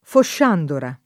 [ fošš # ndora ]